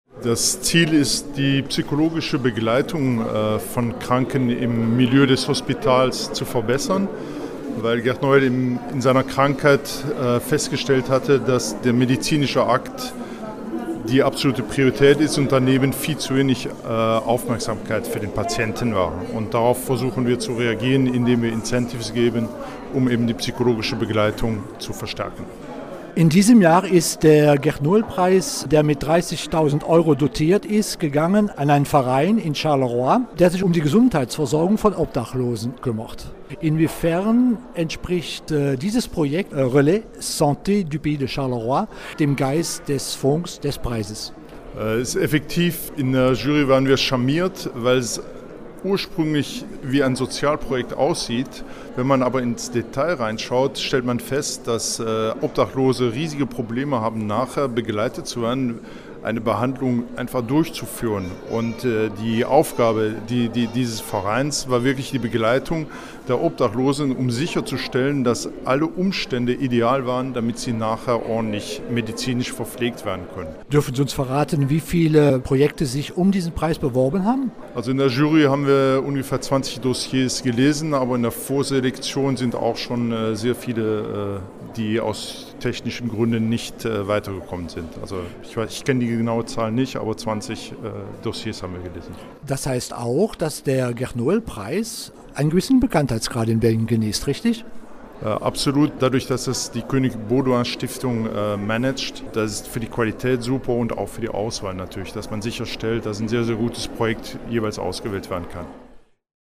aus Brüssel